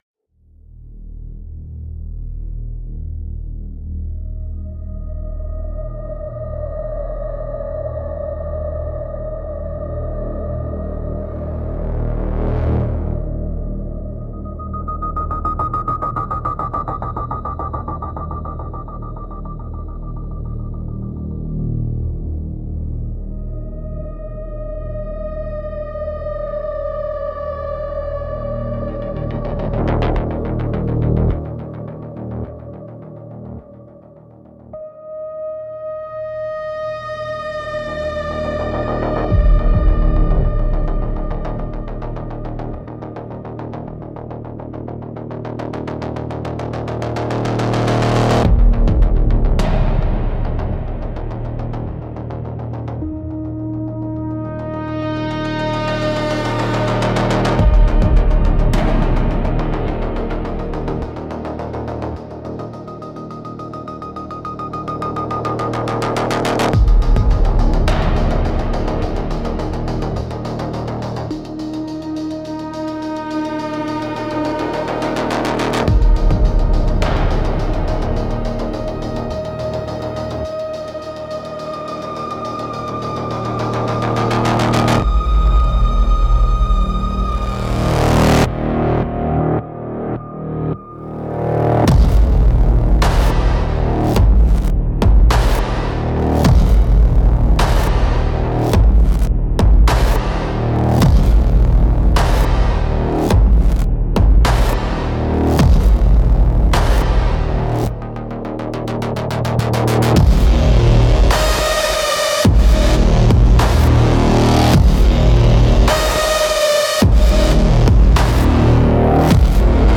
Instrumental - Corroded Memory Palace 3.41